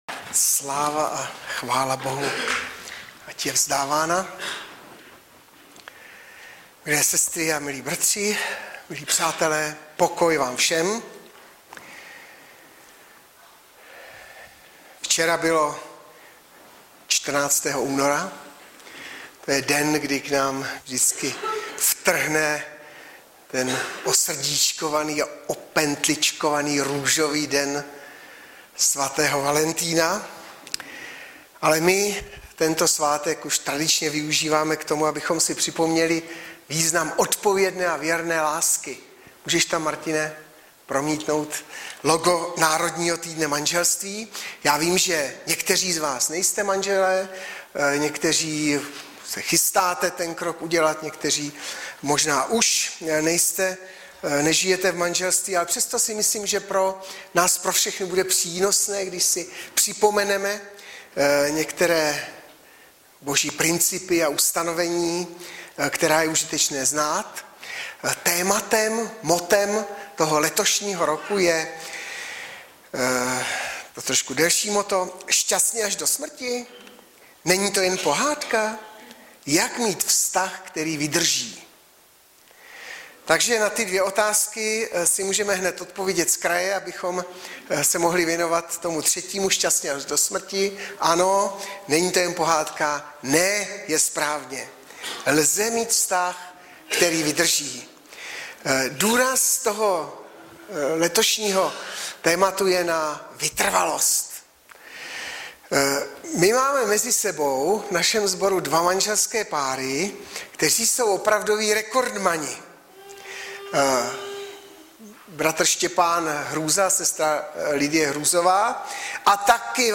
Webové stránky Sboru Bratrské jednoty v Litoměřicích.
Audiozáznam kázání